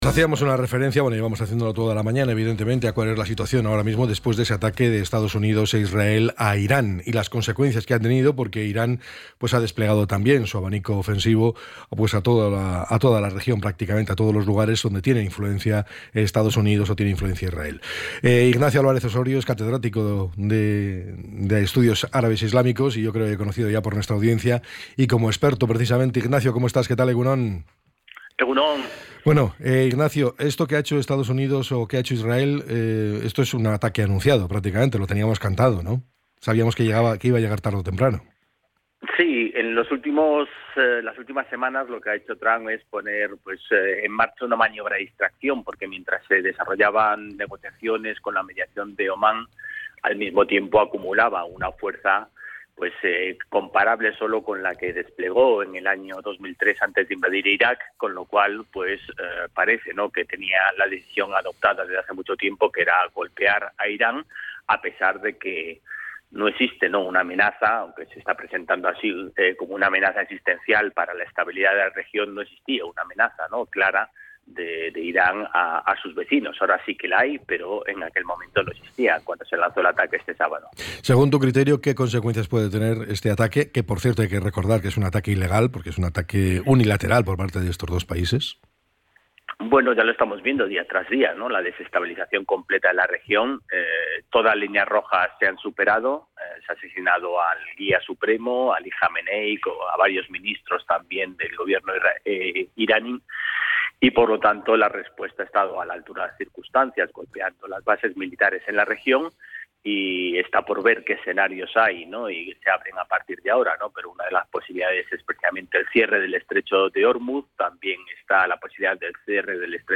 En una entrevista para el programa EgunOn Bizkaia